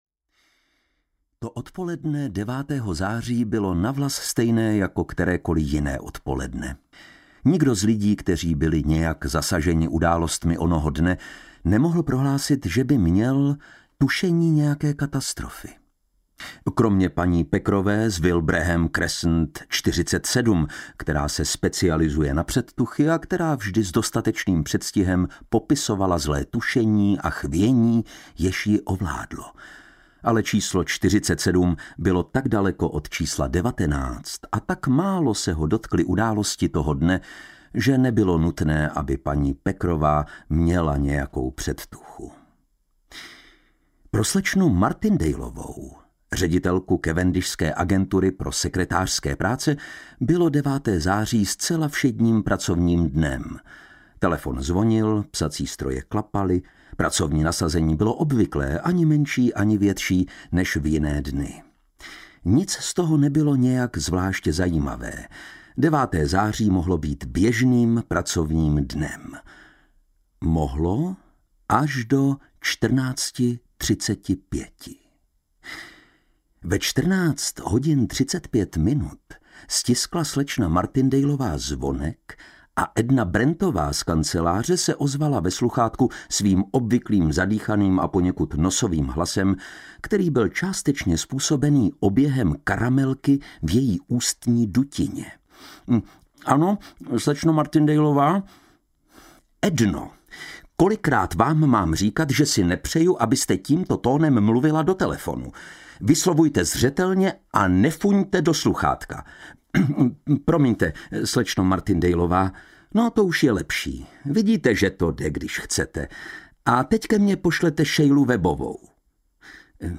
Hodiny audiokniha
Ukázka z knihy
• InterpretLukáš Hlavica